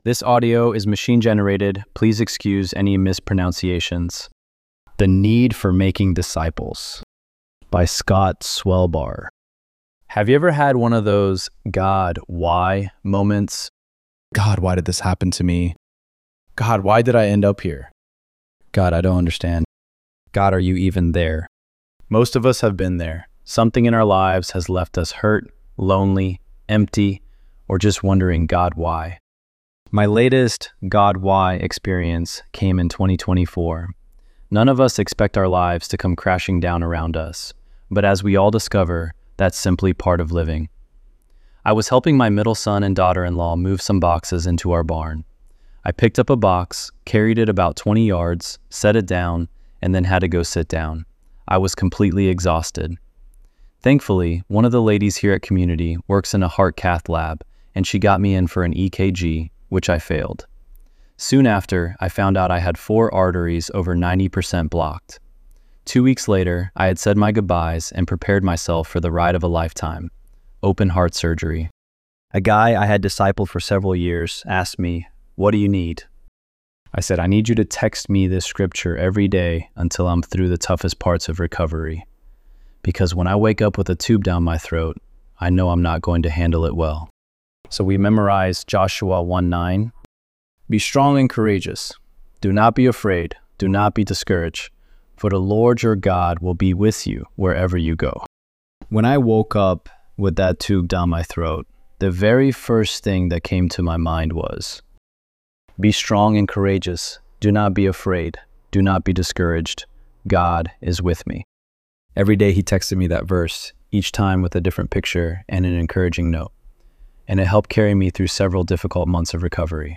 ElevenLabs_3_20.mp3